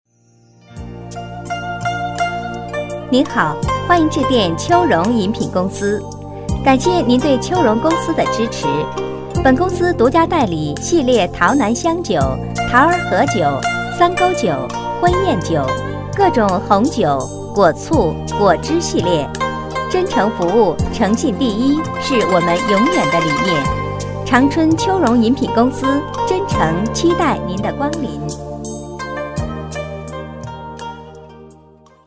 女声配音
彩铃女国55